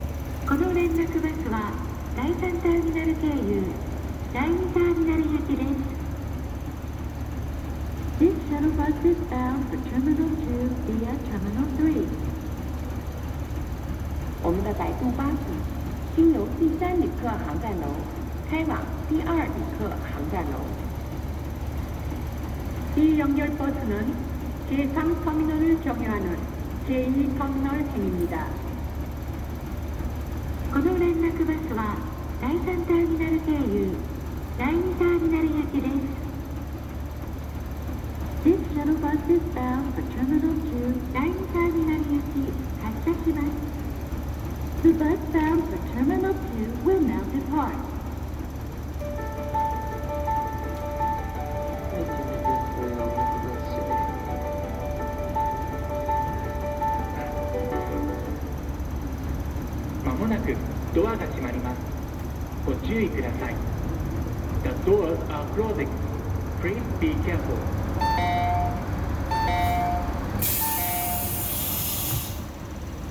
なぜか空港の無料ターミナル連絡バスには発車メロディが搭載されています
melody.mp3